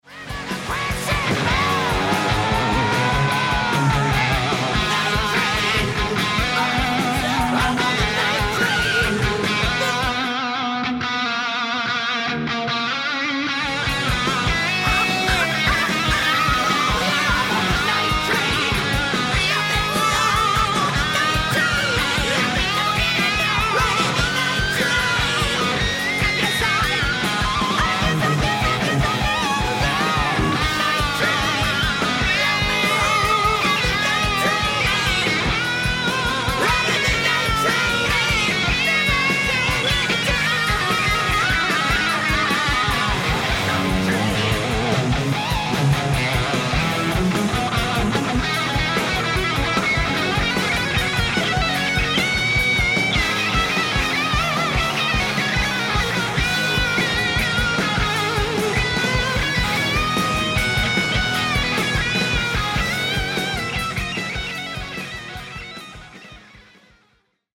Captured from real gear and dialled in to get that tone.